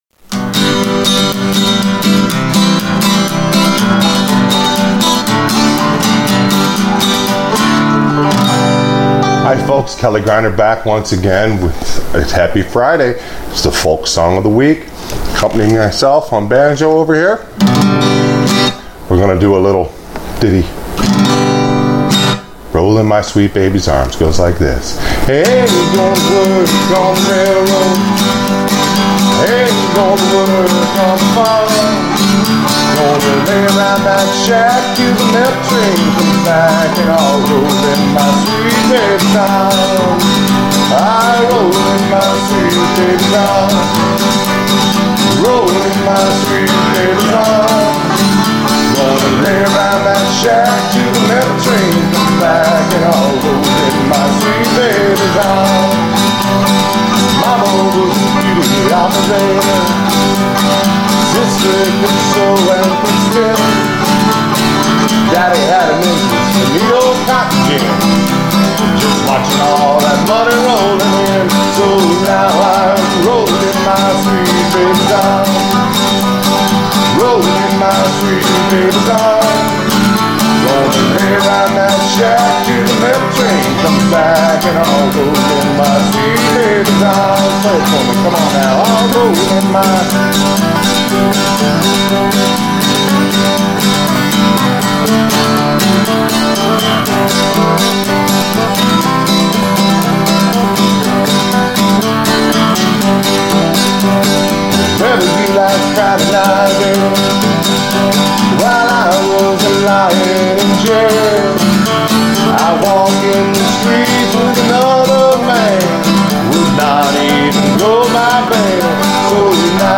Once again, I am trying out the Green Screen technique to see if it helps show you how to play along with someone else.